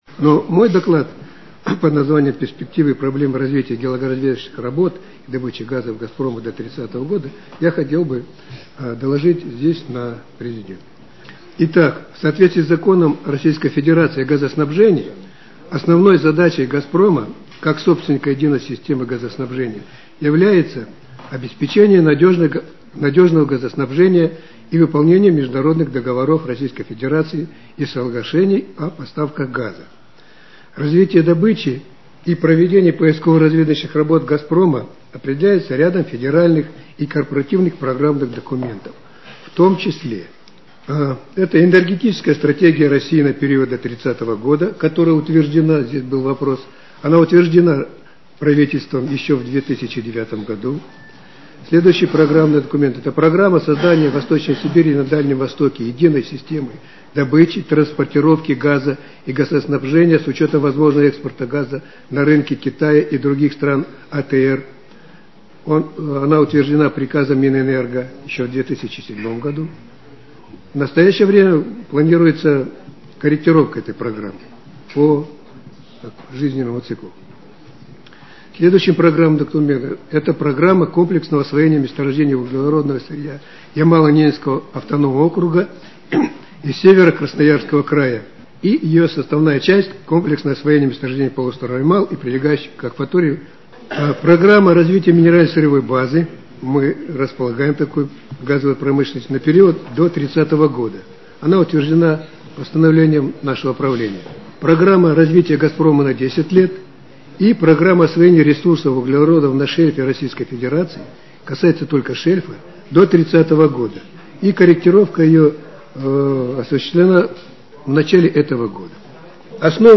2 июня 2011 г. на заседании Президиума СО РАН прозвучали доклады